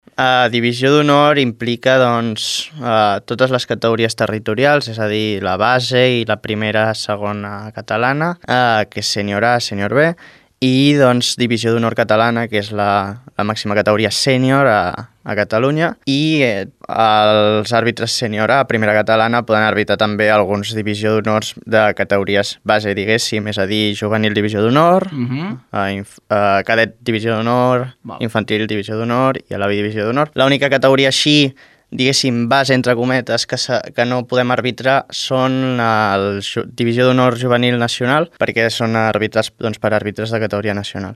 Ahir al vespre en declaracions al Show diari detallava les categories que ja pot xiular des d’aquest curs.